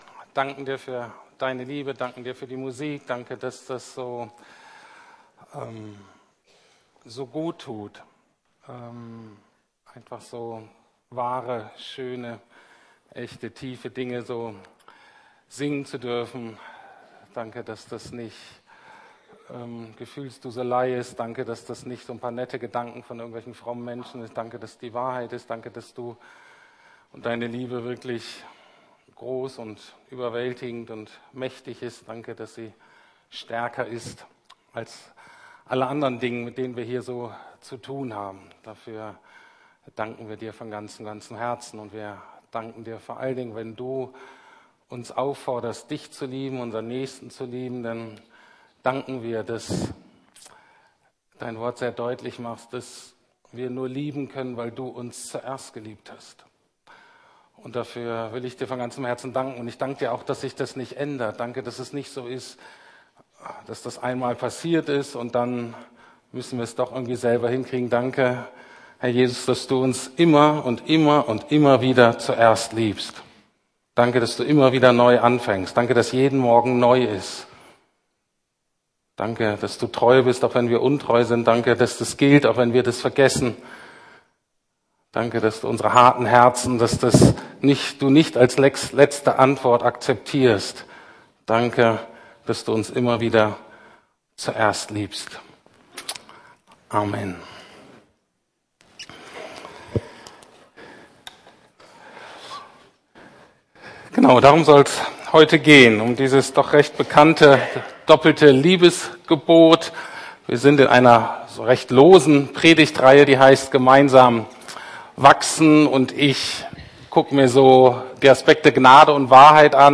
Gemeinsam wachsen in Gnade und Wahrheit - Teil 3 ~ Predigten der LUKAS GEMEINDE Podcast